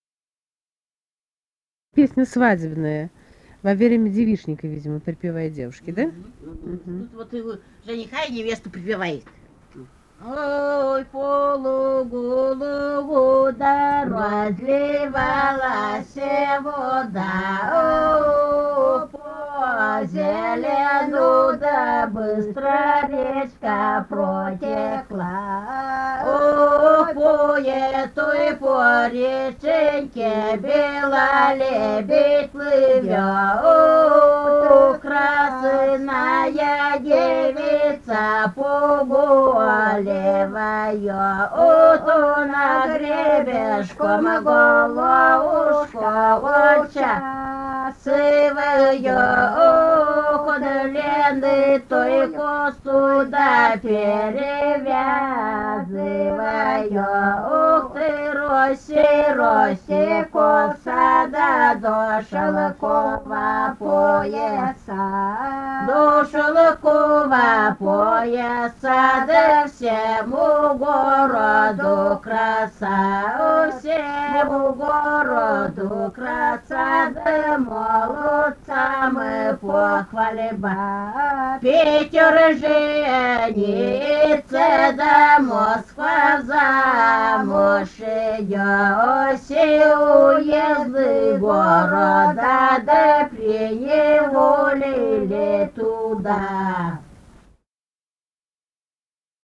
«Ой, по лугу, лугу» (свадебная).
Архангельская область, с. Долгощелье Мезенского района, 1965, 1966 гг.